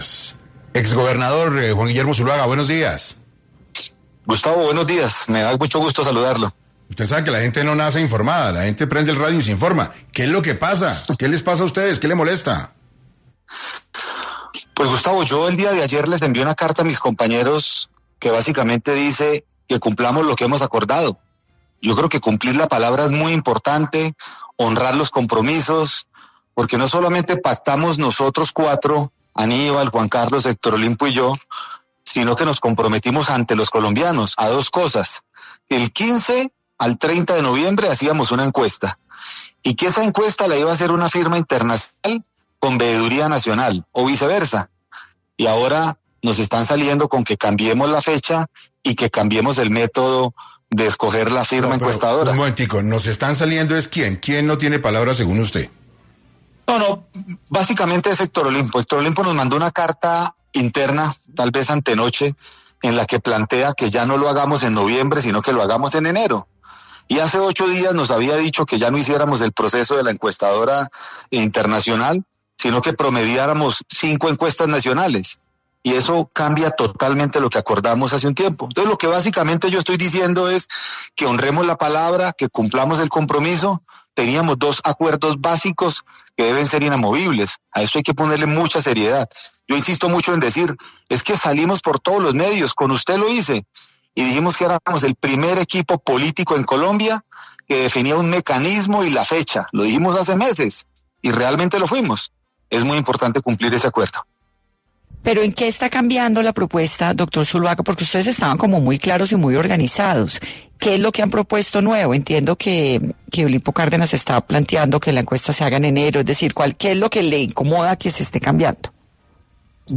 En entrevista con 6AM de Caracol Radio, Zuluaga aseguró que envió una carta a sus compañeros de coalición, instándolos a honrar los compromisos previamente establecidos.